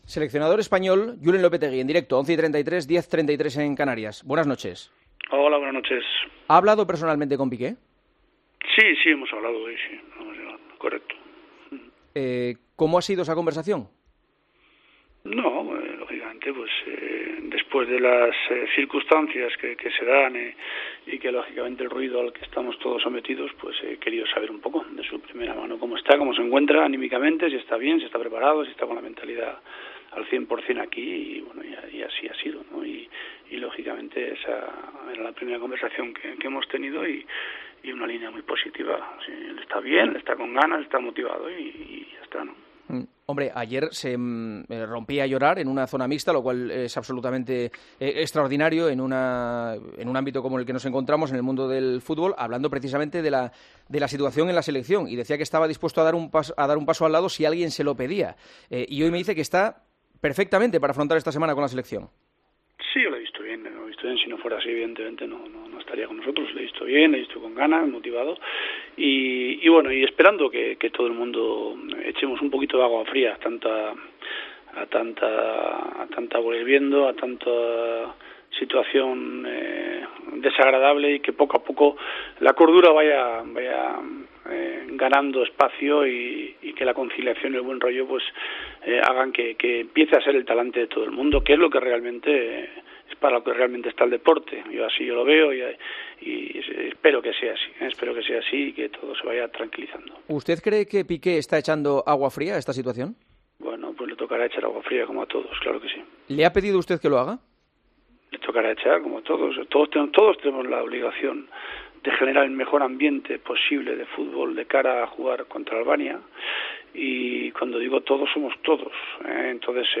Este lunes, El Partidazo de COPE comenzó con una llamada al seleccionador Julen Lopetegui para hablar de la tensión generada en torno a Gerard Piqué .